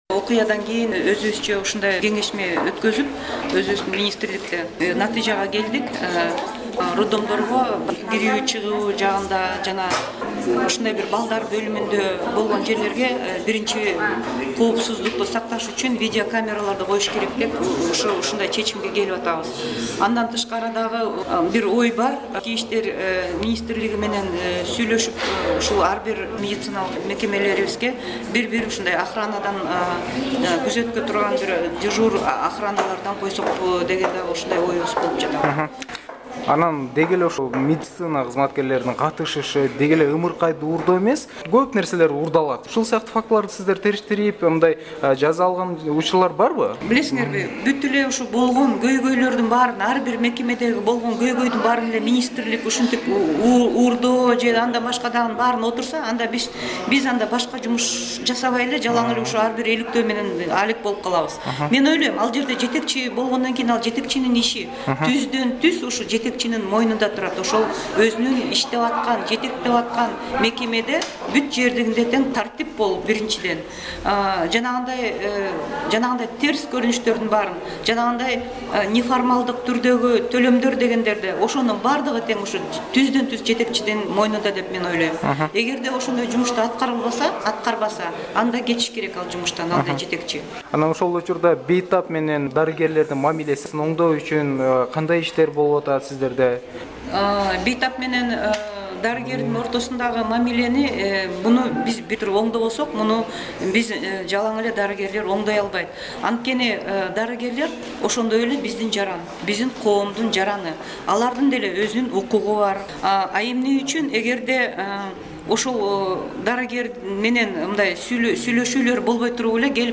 KYRGYZ/HEALTH/ Special Radio Package: Interview with Dinara Sagynbaeva, Kyrgyz Health minister on measures to improve safety in hospitals and relationship between doctor and patient.